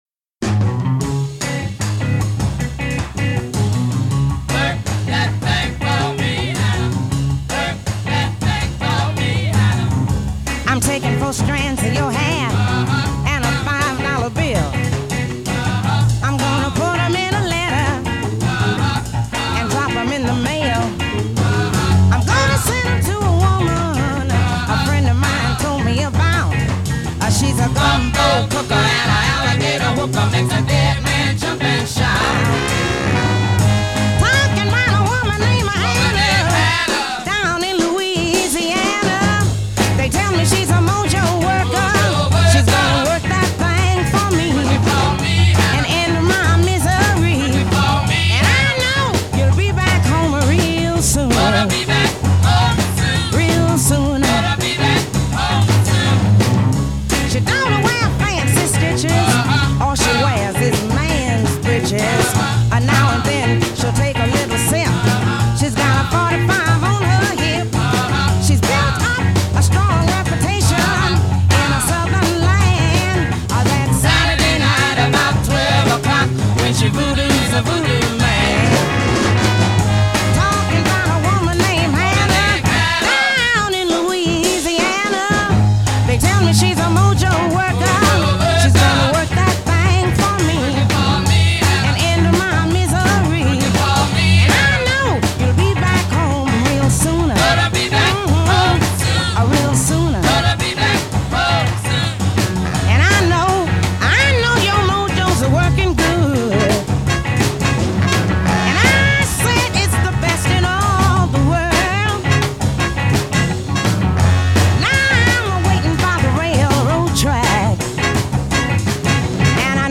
Rhythm & Blues cut from one of the GREAT female vocalists.